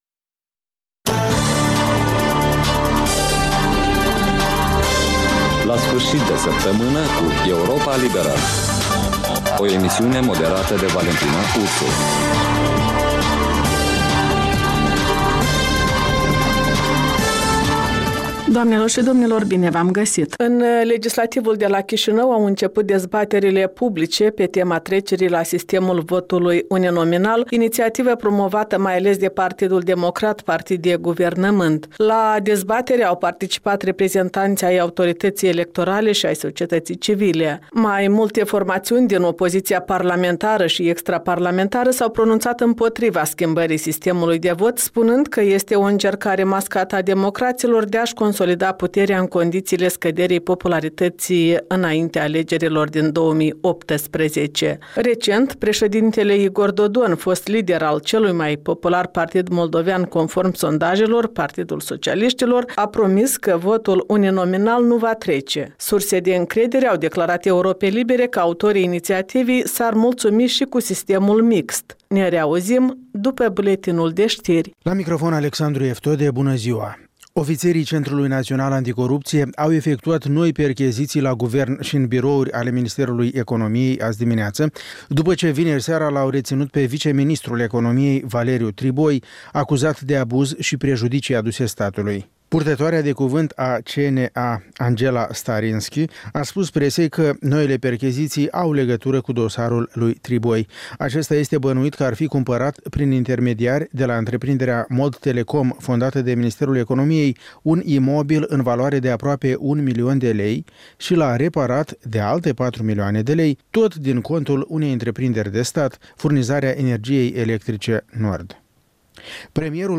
In fiecare sîmbătă, un invitat al Europei Libere semneaza „Jurnalul săptămînal”.